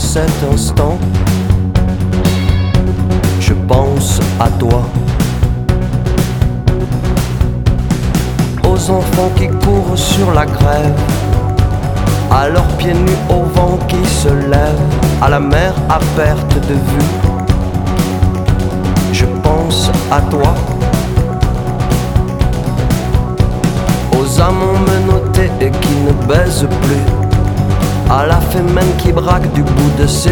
"frPreferredTerm" => "Chanson francophone"